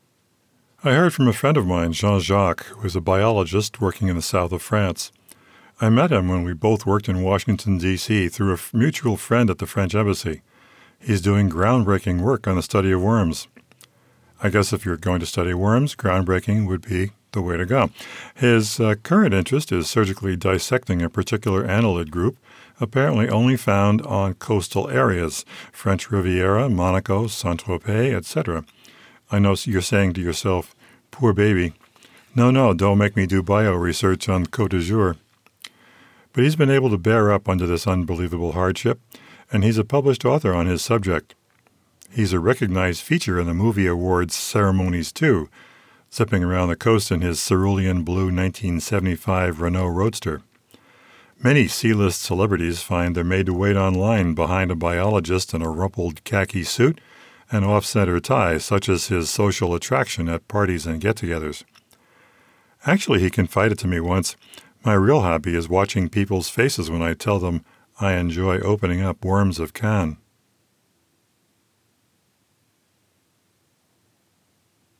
This is an insanely bad reading of a short story I wrote a while ago.
In my quiet third bedroom. The raw work has the main AudioBook Mastering suite applied to it.
Rumble, RMS (loudness), Peak.
And exactly one edit. I badly fluffed and re-read one sentence. No noise reduction.
Music Memo is built for music accuracy and for better or worse, that’s exactly what I sound like.
I presented into the rear of the unit, that being the “front” of the microphone.
Yes, I retain my verbal ticking.